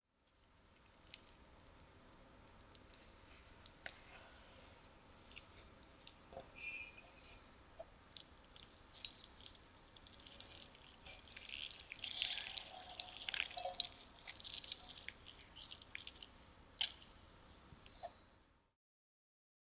Капающая жидкость
kapayushaya_zhidkost_mbg.mp3